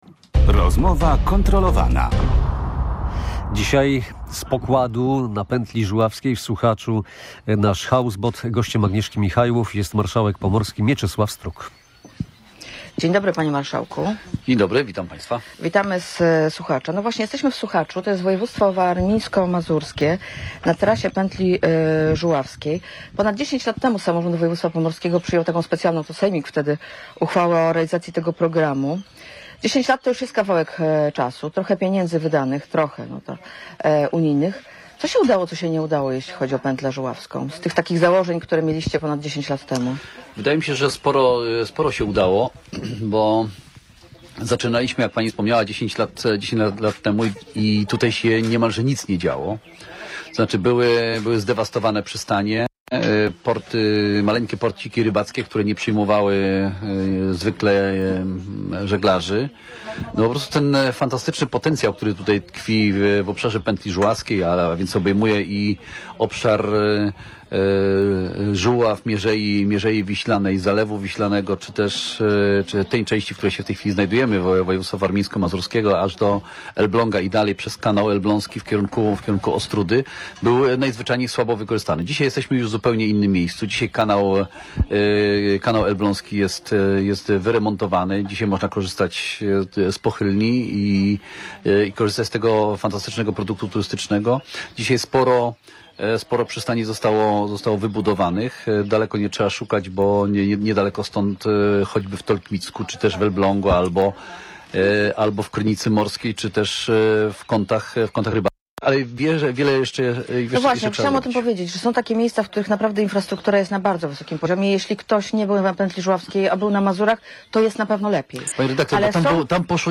Ale wiele jeszcze jest do zrobienia – mówił w Radiu Gdańsk Mieczysław Struk, marszałek województwa pomorskiego. Polityk podsumował działanie uchwały o realizacji programu zagospodarowania terenów Pętli Żuławskiej, którą samorząd województwa pomorskiego przyjął dekadę temu.